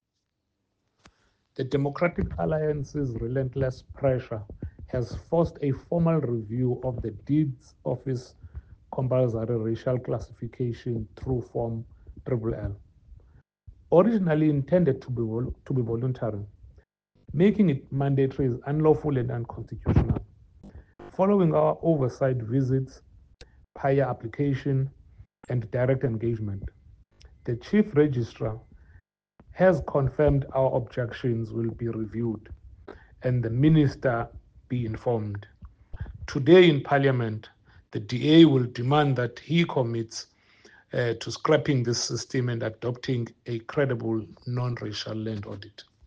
Soundbite by Mlindi Nhanha MP